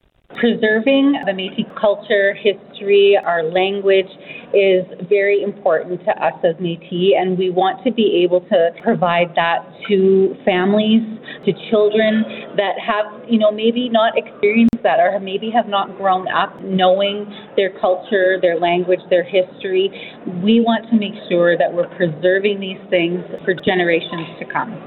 Sandmaier goes on to say that the key importance with creating the Child Care Facilities is to help preserve the Métis Culture, language and teachings for many generations to come.